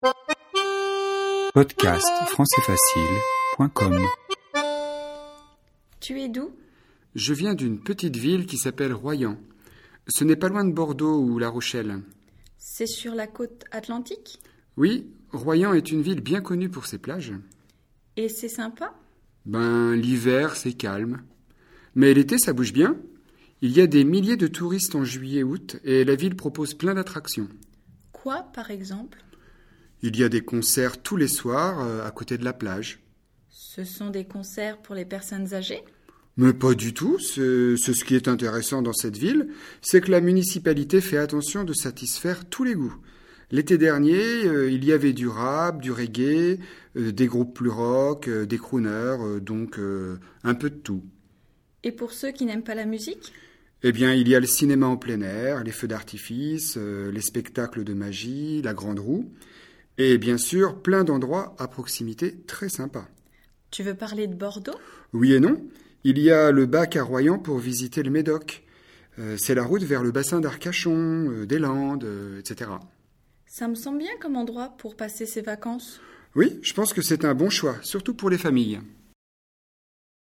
3ème dialogue FLE, niveau intermédiaire (A2) sur le thème villes et régions.